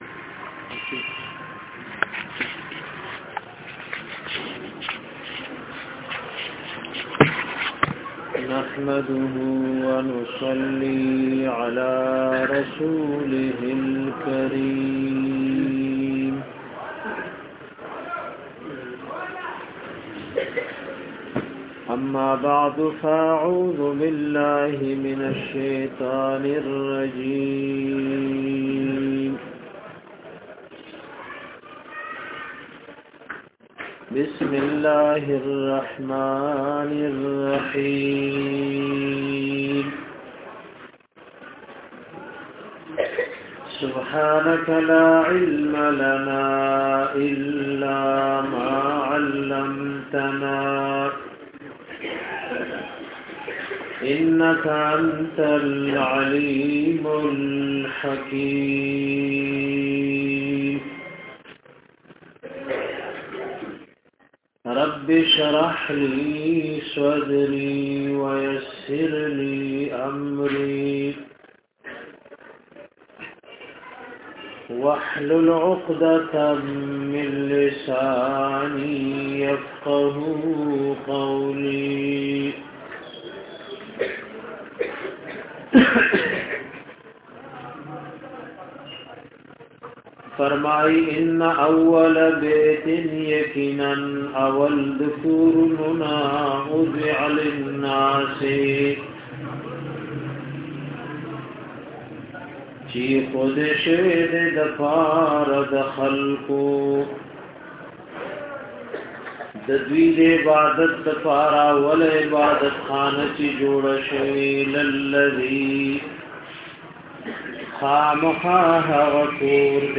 DARSE E QURAN